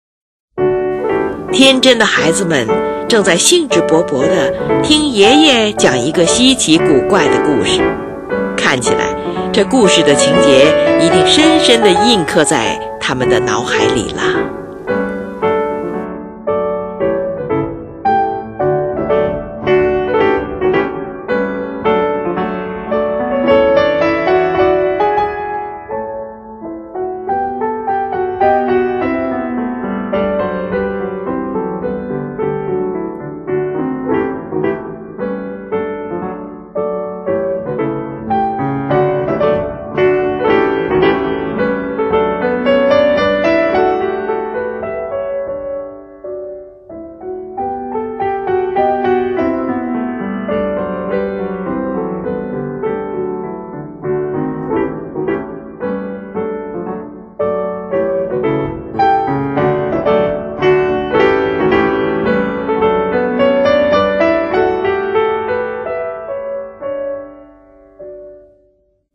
作品手法精炼形象，刻画生动准确，心理描写逼真，欢快动人，饶有情趣，但也流露出一种因为童年逝去而产生的惆怅感。
乐曲反复出现这种节奏型来刻画故事在孩子心灵中留下的难忘印象！